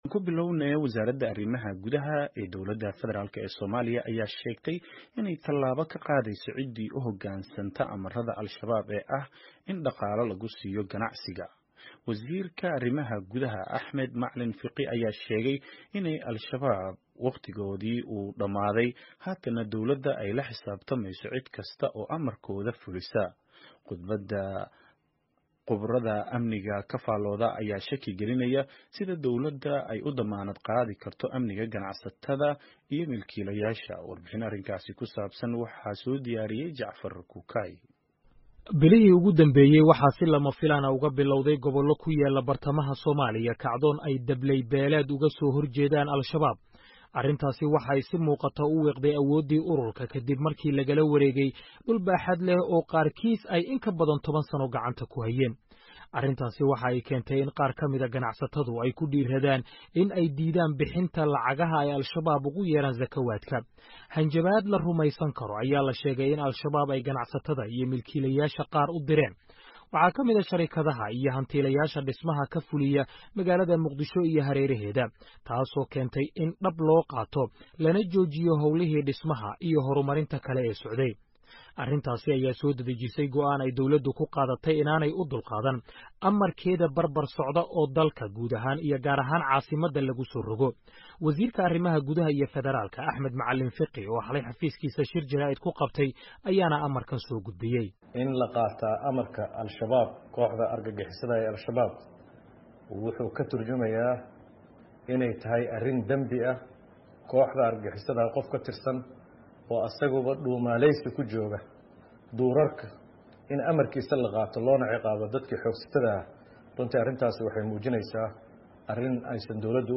Warbixin arrinkaasi ku saabsan